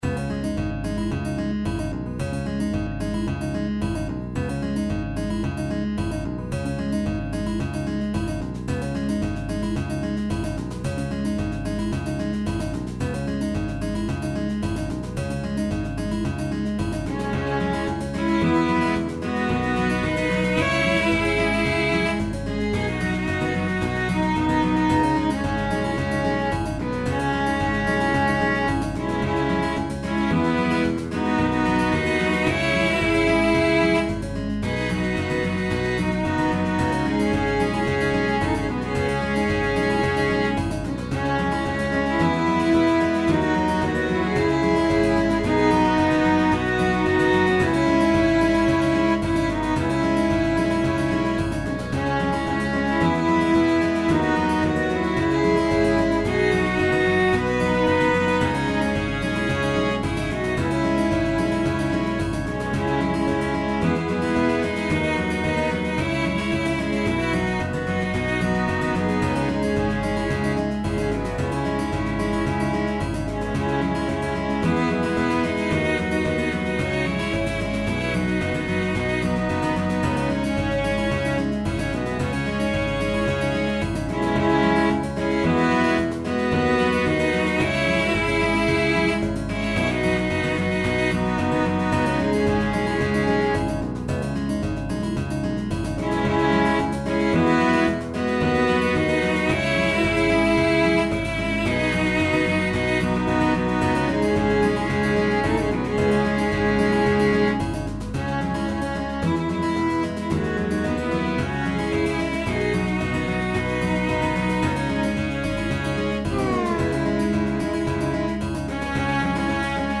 SAB + piano/band